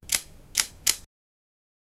The sharp crack of a whip being snapped. 0:11 Created Sep 5, 2024 11:34 PM sound of old camera shutter 0:02 Created Dec 14, 2024 10:54 PM Superman's next snapping in half 0:15 Created Oct 13, 2024 12:34 AM
sound-of-old-camera-shutt-w73euuxm.wav